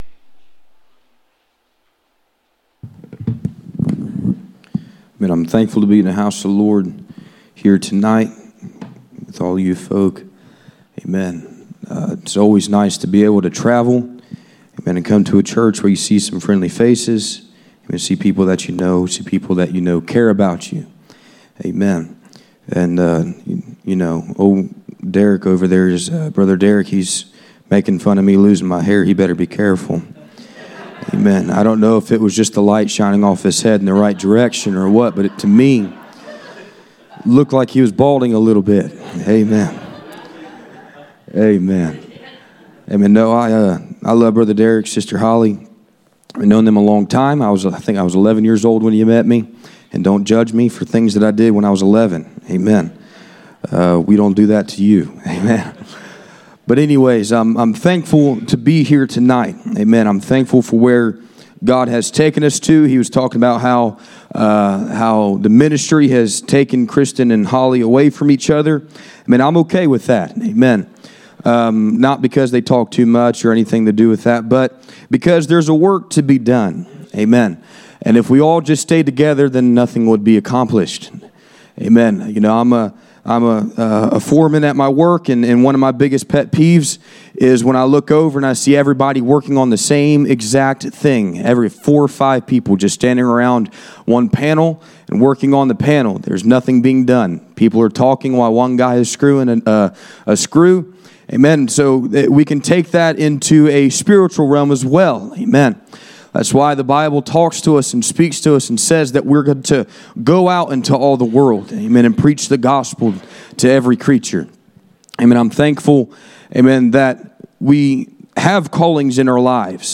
Colossians 1:15-23 Service Type: Sunday Evening %todo_render% « Grooming for Glory